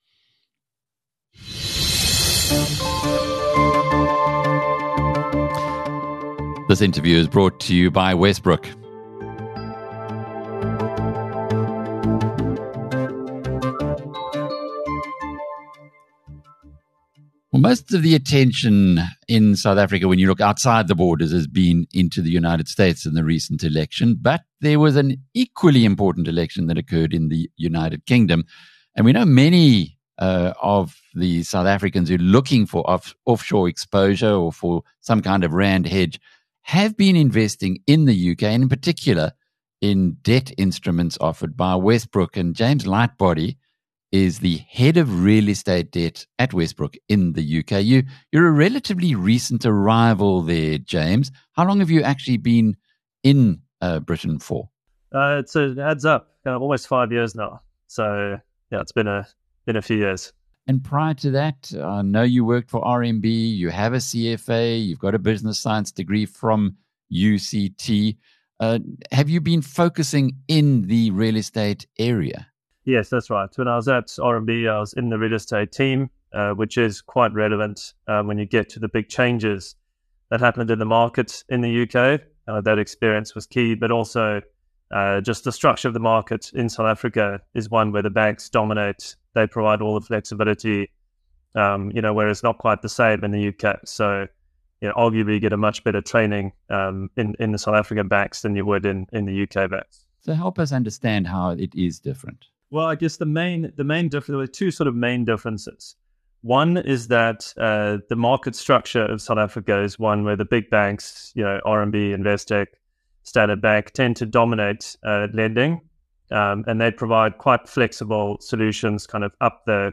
discusses the evolving landscape of the UK real estate debt market in an interview with Alec Hogg. He compares the UK’s conservative lending environment to South Africa’s bank-dominated market, explaining how rising interest rates and political changes have created new opportunities for specialised lenders like Westbrooke.